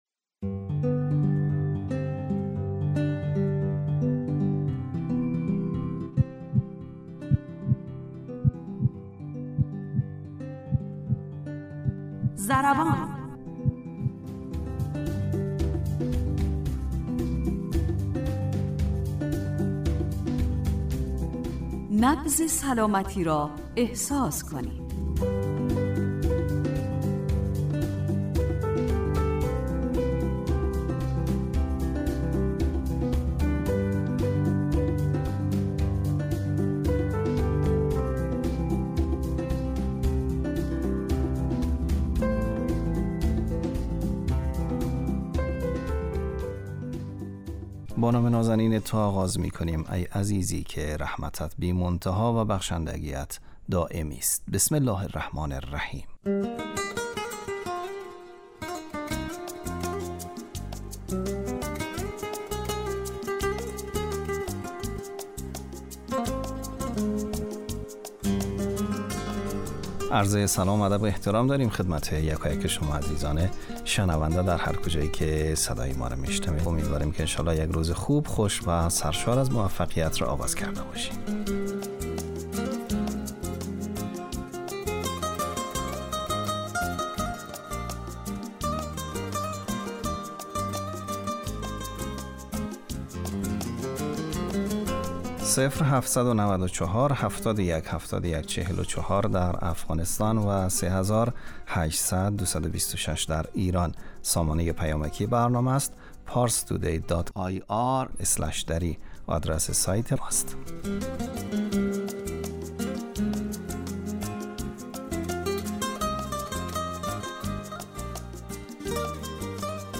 برنامه ضربان، برنامه ای صحی و بهداشتی است که با استفاده از تجربیات کارشناسان حوزه بهداشت و سلامت و استادان دانشگاه، سعی دارد مهمترین و شایع ترین مشکلات صحی...